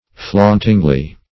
flauntingly - definition of flauntingly - synonyms, pronunciation, spelling from Free Dictionary Search Result for " flauntingly" : The Collaborative International Dictionary of English v.0.48: Flauntingly \Flaunt"ing*ly\, adv.
flauntingly.mp3